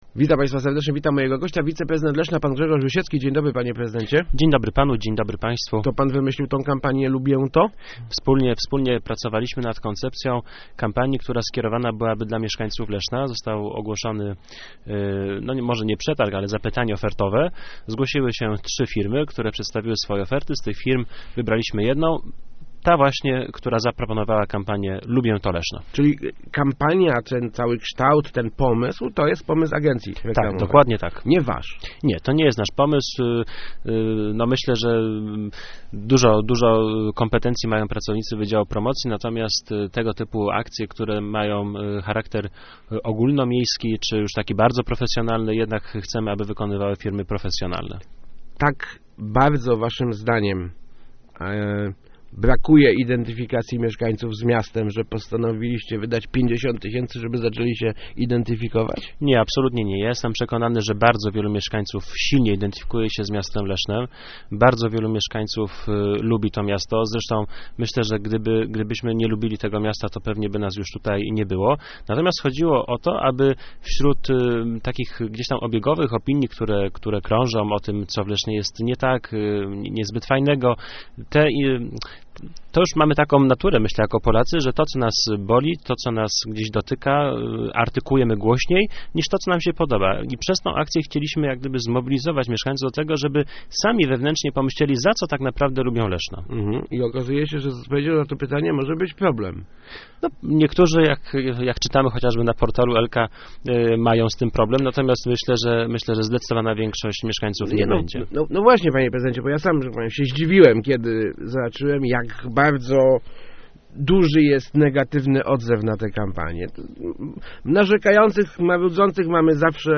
Ta kampania nie ma zmienić rzeczywistości ani tym bardziej jej zniekształcić - mówił w Rozmowach Elki wiceprezydent Grzegorz Rusiecki, odpowiedzialny na rozpoczynającą się we wtorek akcję "Leszno. Lubię to!".